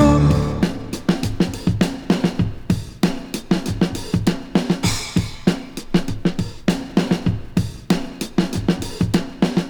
• 99 Bpm Breakbeat Sample D Key.wav
Free drum loop sample - kick tuned to the D note. Loudest frequency: 999Hz
99-bpm-breakbeat-sample-d-key-OGA.wav